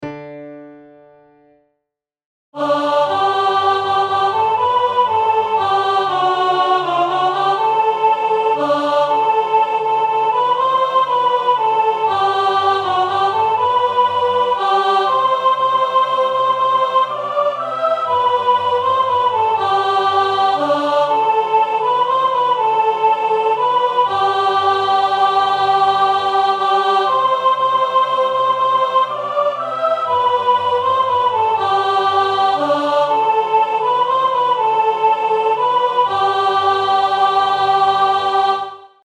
Bajazzo, Deutsches Volkslied
Tempo- und Lautstärken-Variationen sowie andere Ausdrucksvorgaben wurden nur wenig berücksichtigt.
BajazzoAlt.mp3